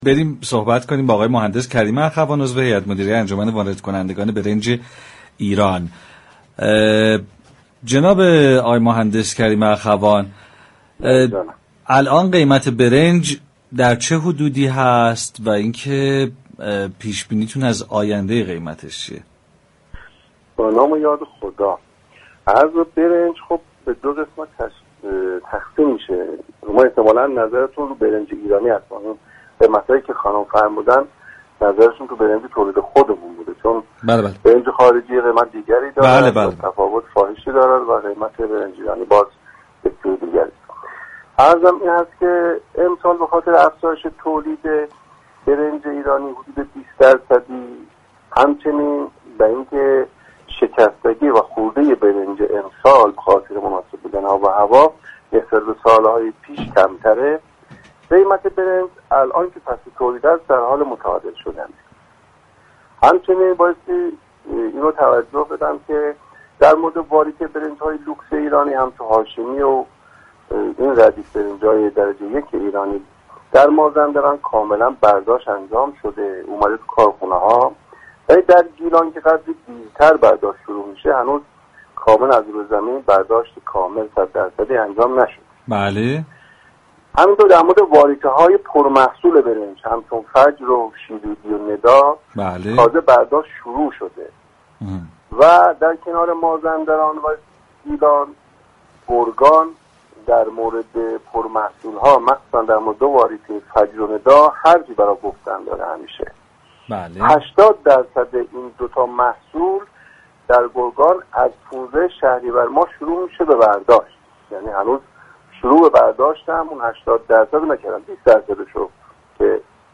در گفت‌وگو با برنامه فرحزاد رادیو تهران